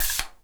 spray_bottle_08.wav